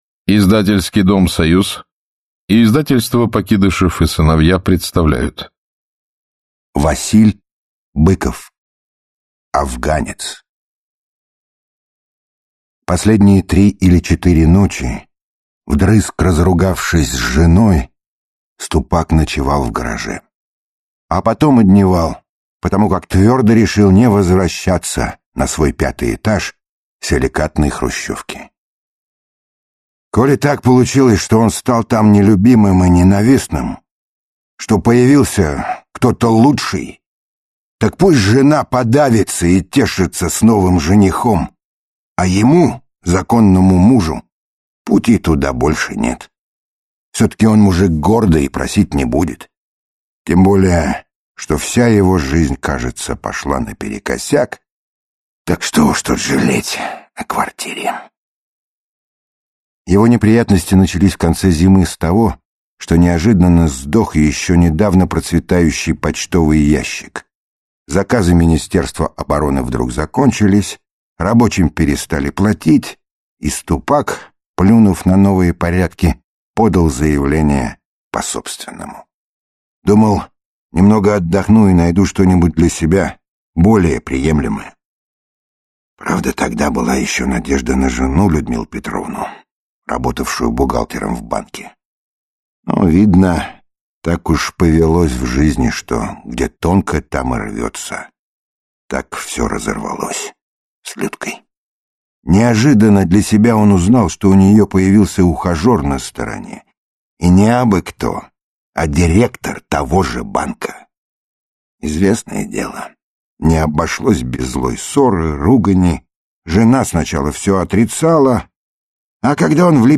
Аудиокнига Афганец | Библиотека аудиокниг
Aудиокнига Афганец Автор Василь Быков Читает аудиокнигу Михаил Горевой.